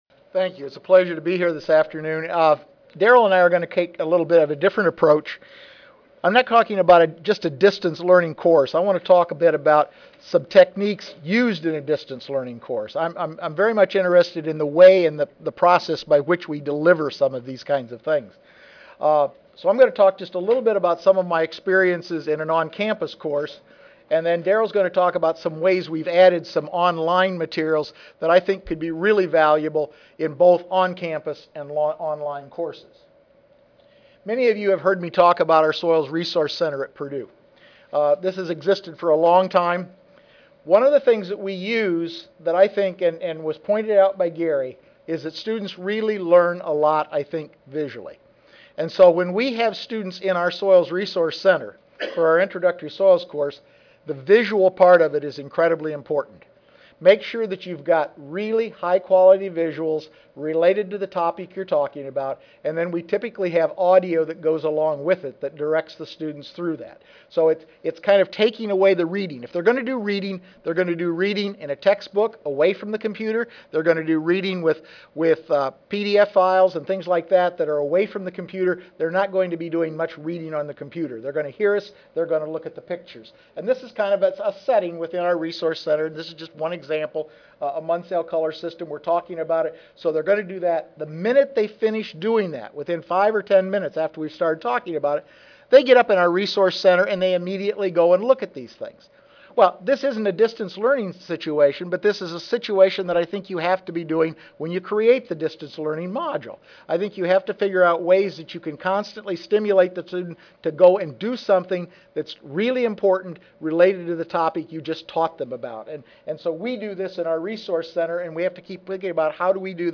S05 Pedology Session: On-Line Education in Soil Science: I (2010 Annual Meeting (Oct. 31 - Nov. 3, 2010))
Purdue University Audio File Recorded presentation